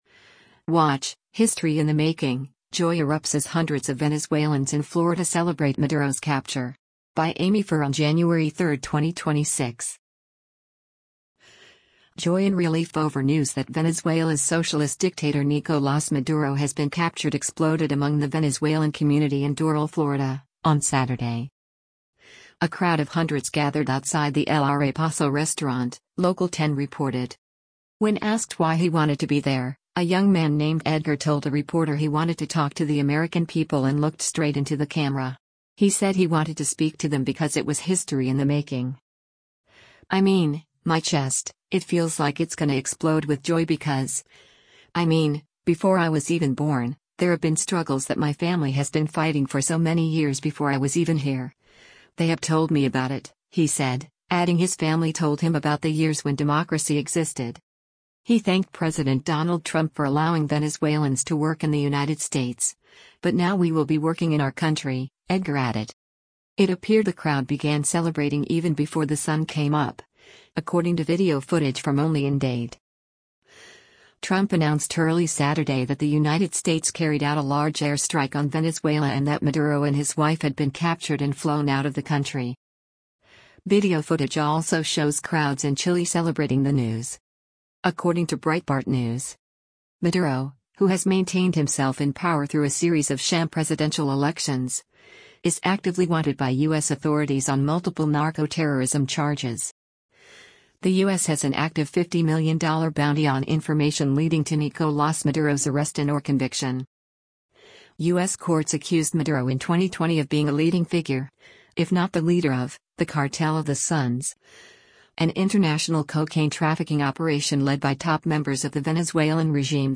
WATCH — ‘History in the Making’: Joy Erupts as Hundreds of Venezuelans in Florida Celebrate Maduro’s Capture
A crowd of hundreds gathered outside the El Arepazo restaurant, Local 10 reported.